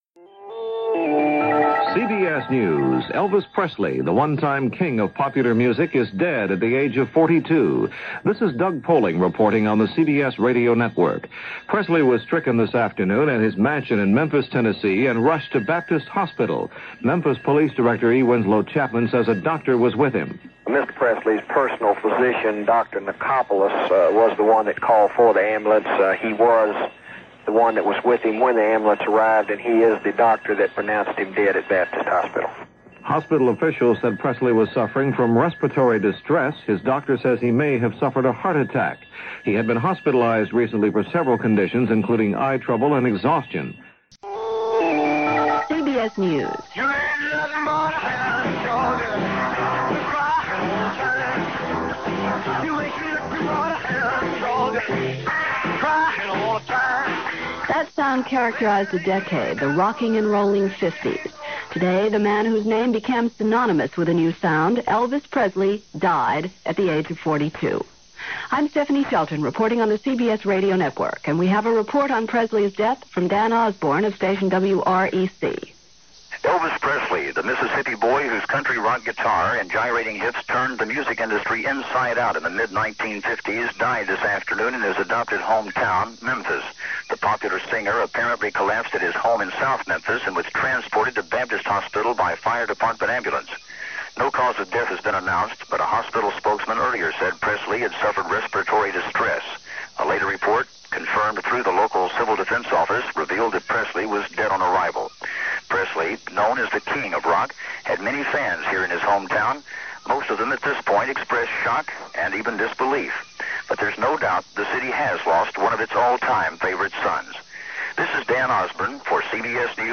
– August 16, 1977 – CBS Radio News + Reports – Gordon Skene Sound Collection –